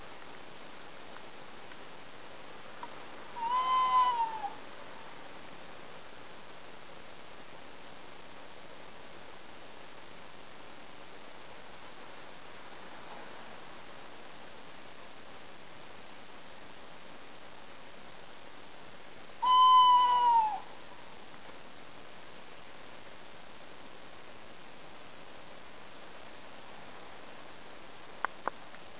描述：Tawny owls TwitTwoo call at in the woods behind Kagyu Samye Ling's 6,30 am on a clear Saturday morning. Background noise from prayer roll motors etc.
标签： twoo Tawny Owl twit
声道立体声